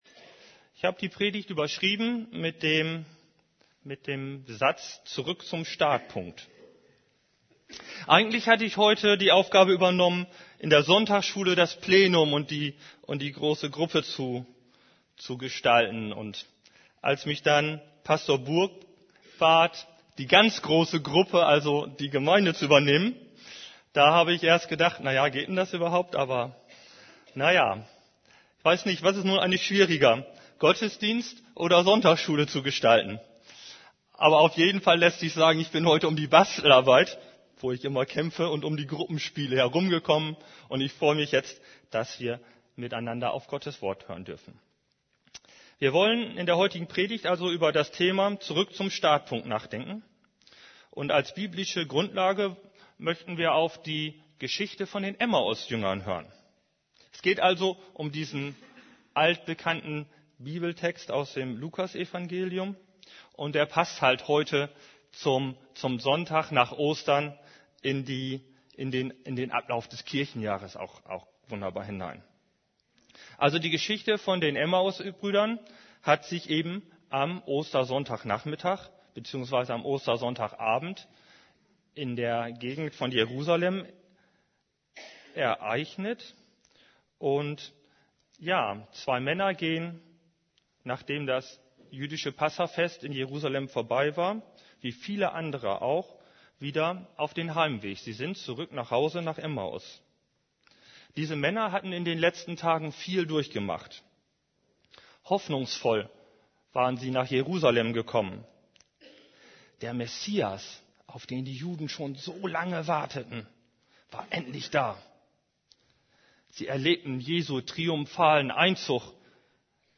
> Übersicht Predigten Zurück zum Startpunkt Predigt vom 15.